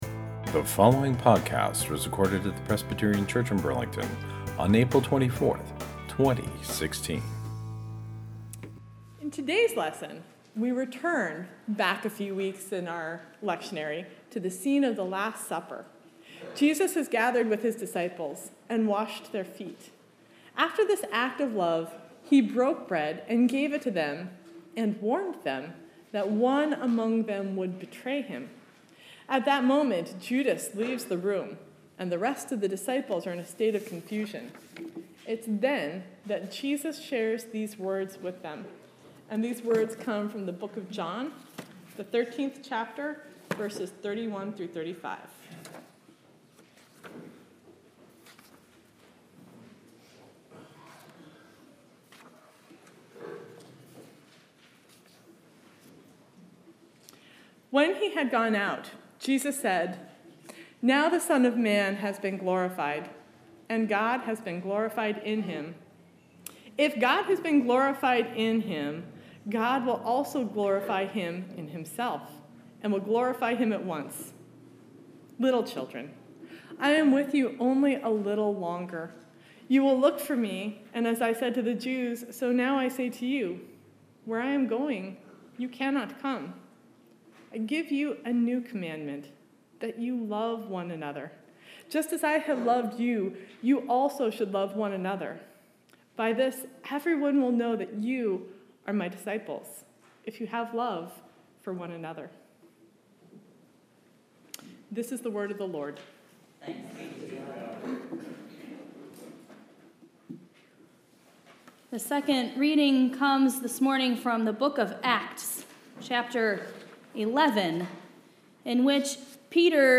Sermon, April 24